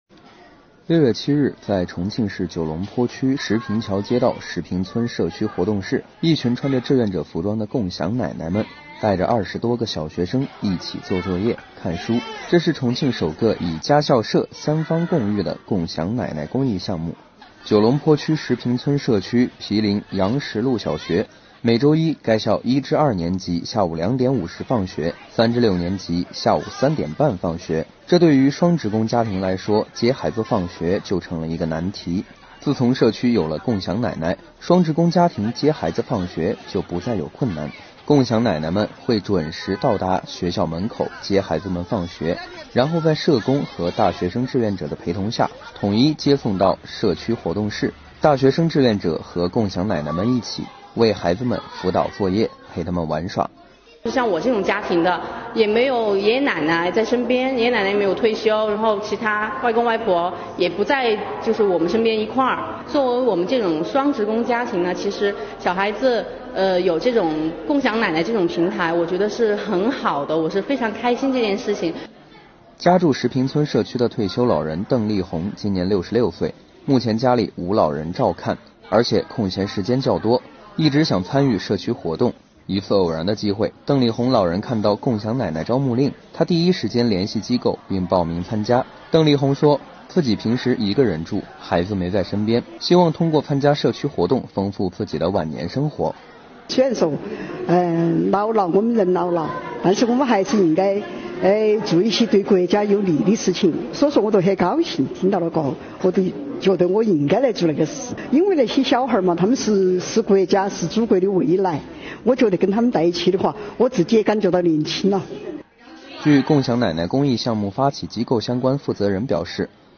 6月7日，在重庆市九龙坡区石坪桥街道石坪村社区活动室，一群穿着志愿者服装的“共享奶奶”们带着20多个小学生，一起做作业、看书，这是重庆首个以“家校社”三方共育的“共享奶奶”公益项目。